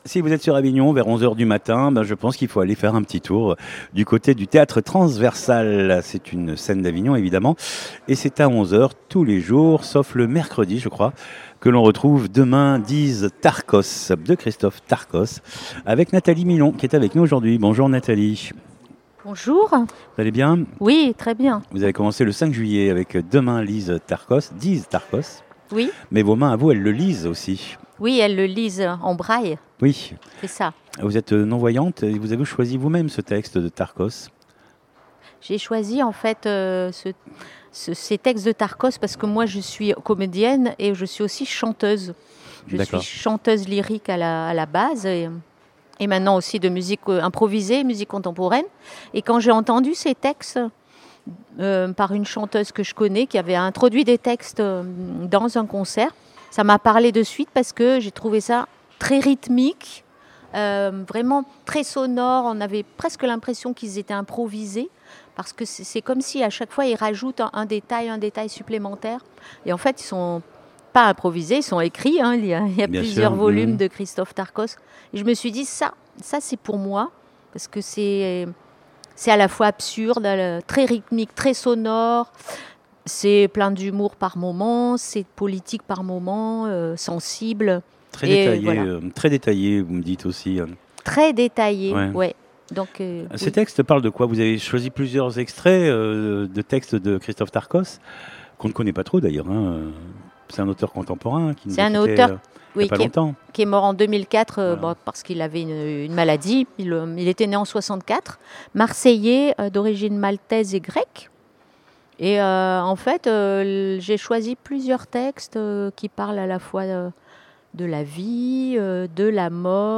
Elle prend la langue de Tarkos, elle la dit, elle la chante, elle la vomit, la caresse, la murmure, la heurte, la bute, la souffle, la mange.